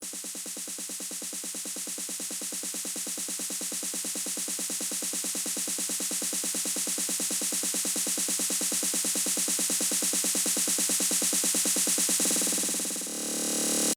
Techno Snare Roll Long
Techno Snare Roll Long is a free music sound effect available for download in MP3 format.
Techno Snare Roll Long.mp3